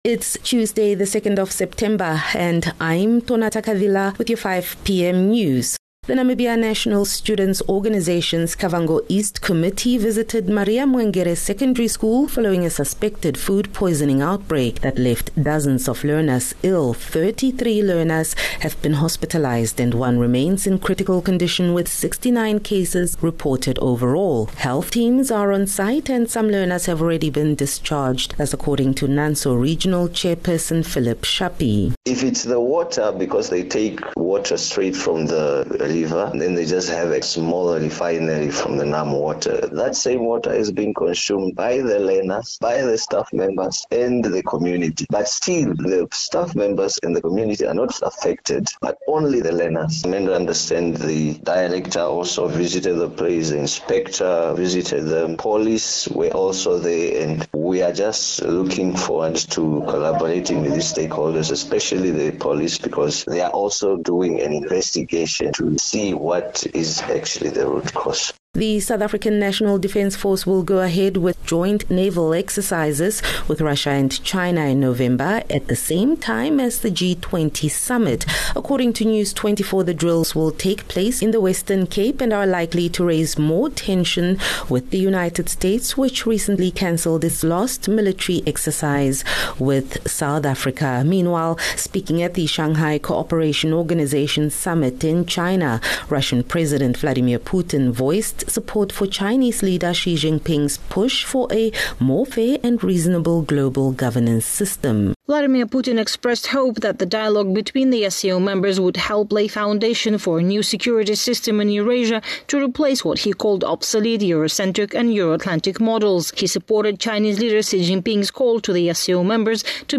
2 Sep 2 September - 5 pm news